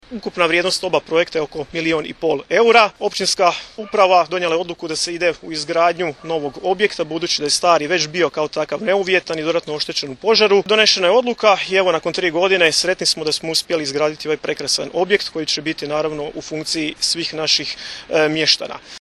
Načelnik Općine Kneževi Vinogradi, Vedran Kramarić, izrazio je svoje zadovoljstvo dovršetkom ovog projekta, istaknuvši kako nova zgrada predstavlja značajan iskorak u funkcionalnosti i estetskom izgledu općinske uprave.